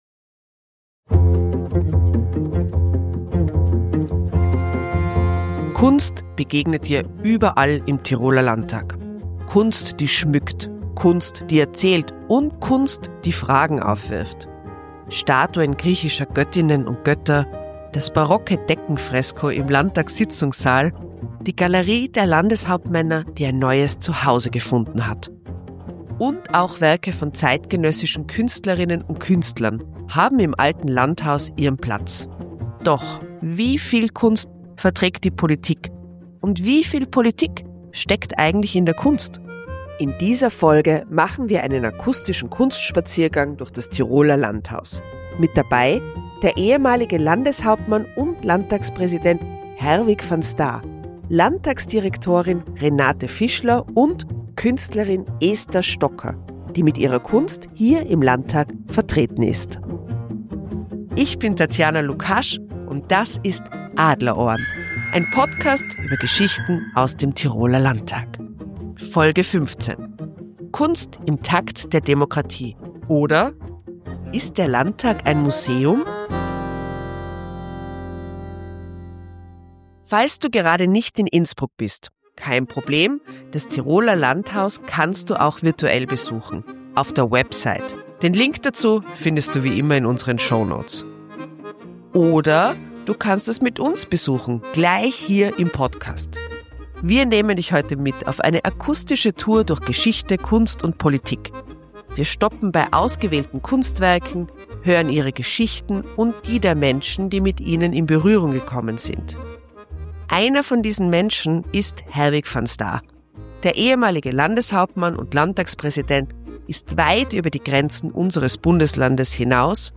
Beschreibung vor 7 Monaten In dieser Episode von "Adlerohren" nehmen wir euch mit auf einen akustischen Kunstspaziergang durch das Alte Landhaus. Dabei erkunden wir die spannende Beziehung zwischen Kunst und Politik. Wir diskutieren die Rolle von Kunst in der politischen Sphäre und die Herausforderungen, die bei der Integration von Kunst in öffentlichen Räumen auftreten.